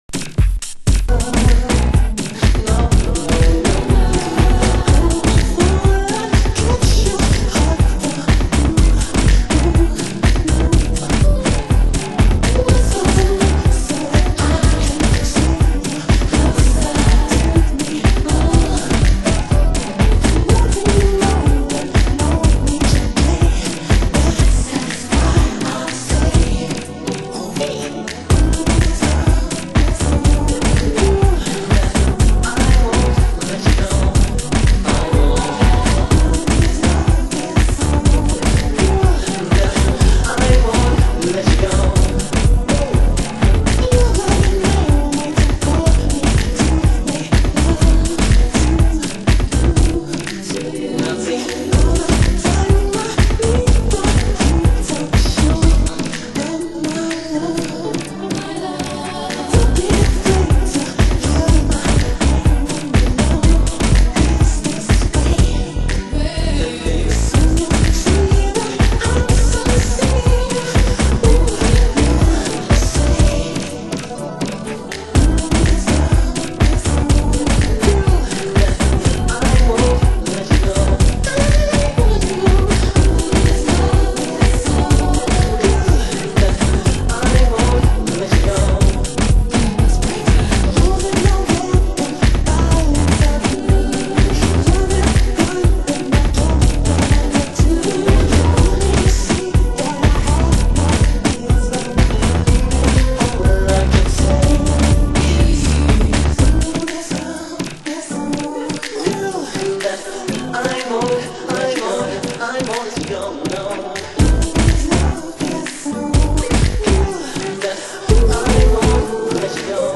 ★DEEP HOUSE 歌 WHITE
Vocal Mix